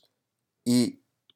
japanese_i_vowel.m4a